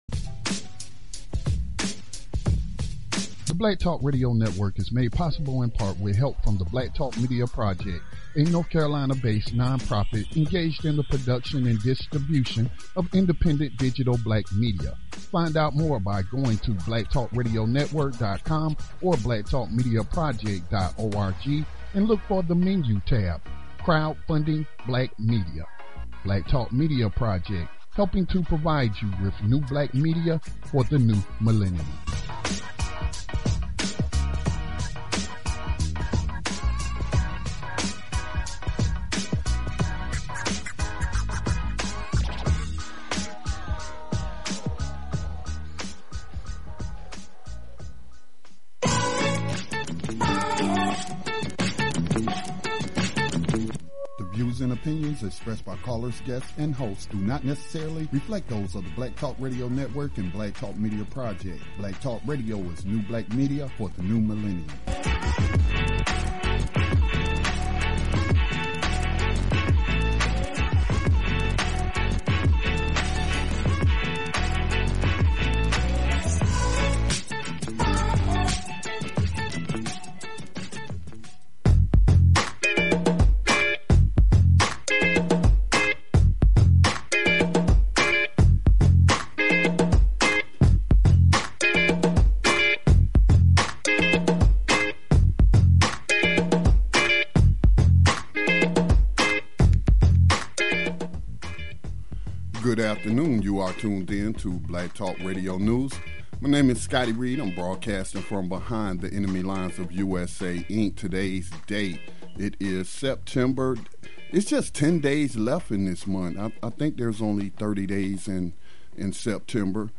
Black Talk Radio: Interview about California Prop 57 – September 20, 2016